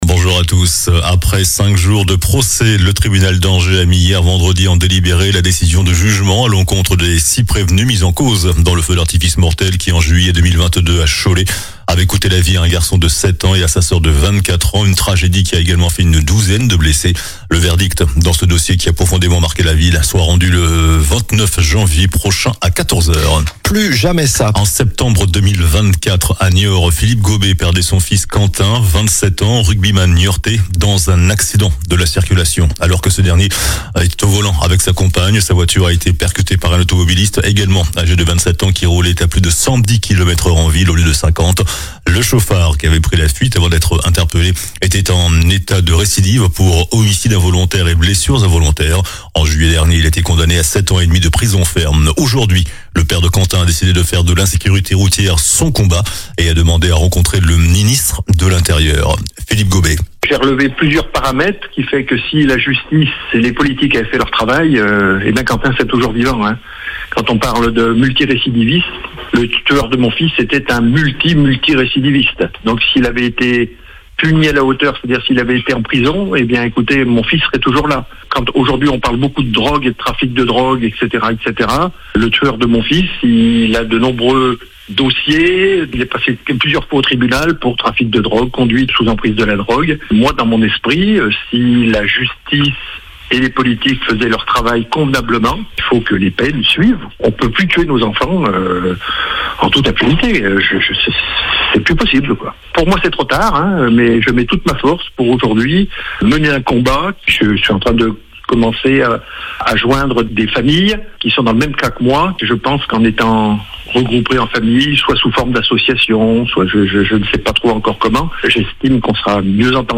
JOURNAL DU SAMEDI 22 NOVEMBRE